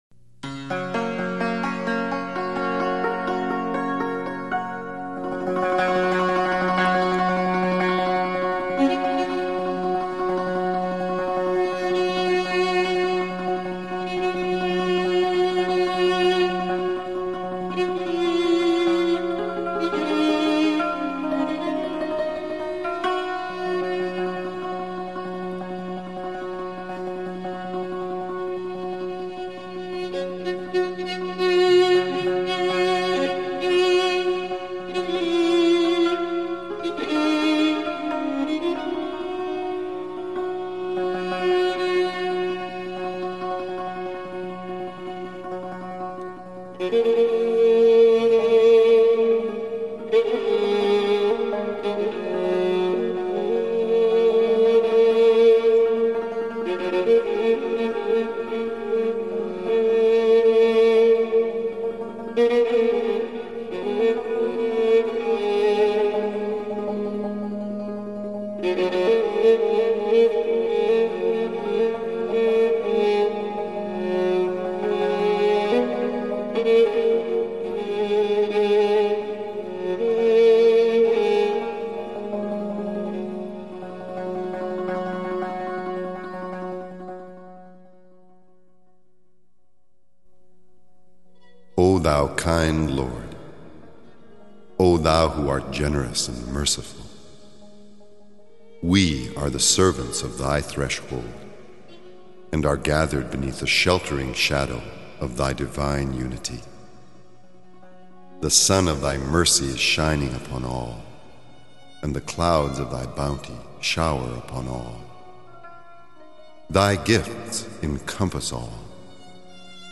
امر جانان حتی در موسیقی اثری بسیار دلنشیت بجا گذاشته و تلفیق موسیقی اصیل ایرانی با کلام و لحن انگلیسی و از همه مهم تر بیان الهی بسیارگرم و دلنشین بود.